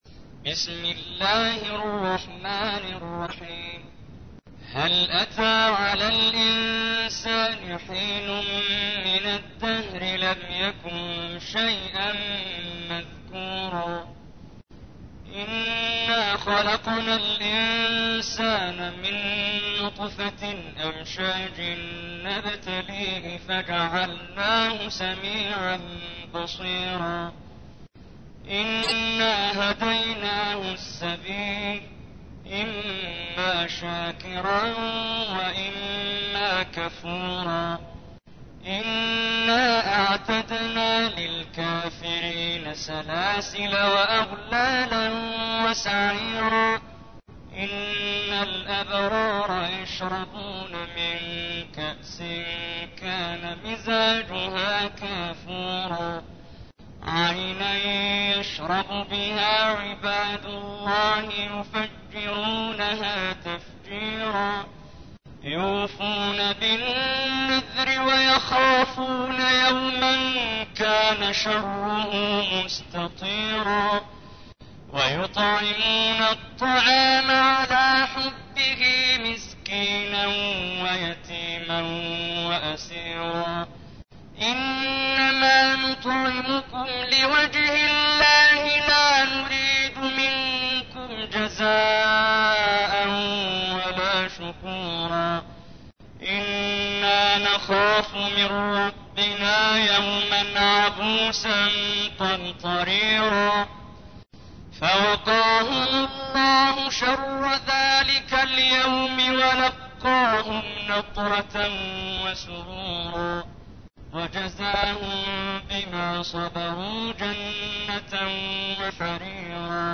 تحميل : 76. سورة الإنسان / القارئ محمد جبريل / القرآن الكريم / موقع يا حسين